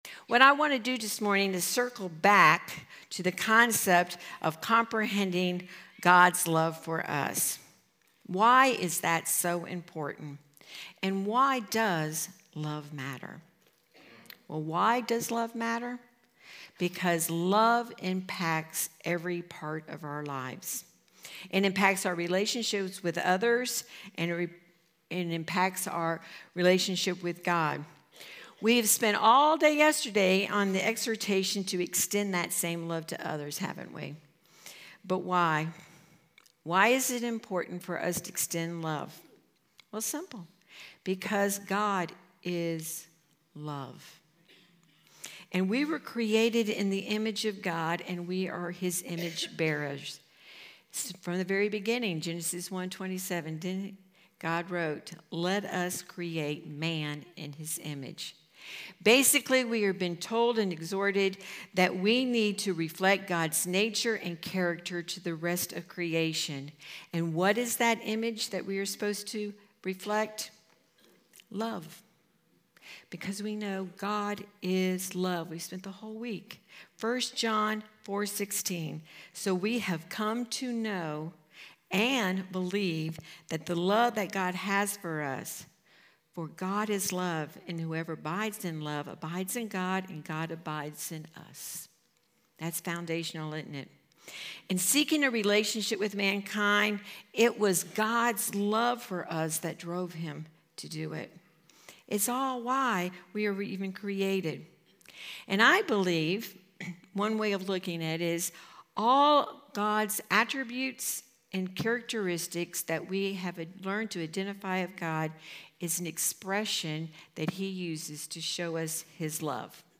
Home » Sermons » Consequences of Love